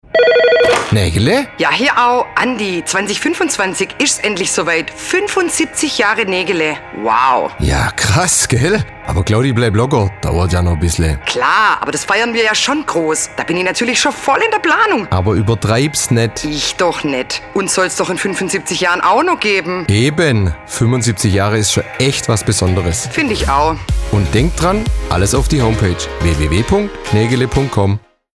Radiowerbung: Teaser 75 Jahre Negele
Presse-Radiospot-Teaser-75-Jahre-Negele.mp3